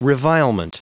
Prononciation du mot revilement en anglais (fichier audio)
Prononciation du mot : revilement
revilement.wav